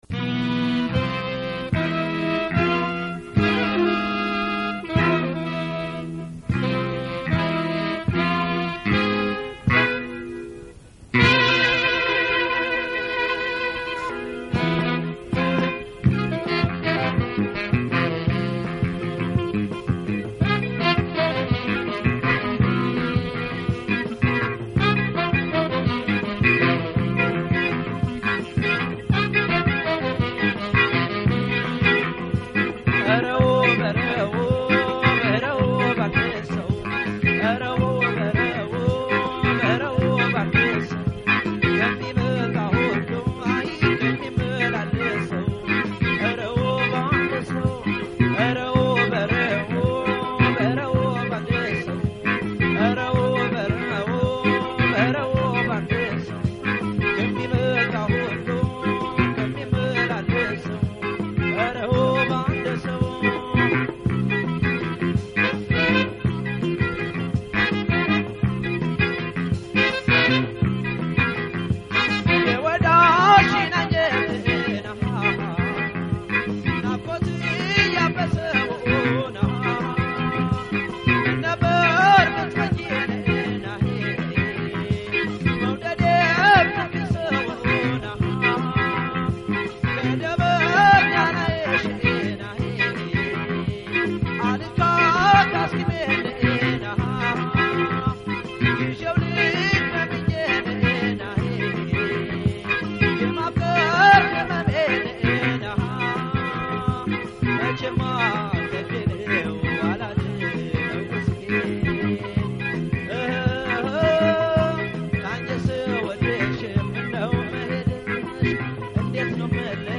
በተባበሩት መንግስታት ድርጅት ጉባኤ የፕሬዚደንት ኦባማ ንግግር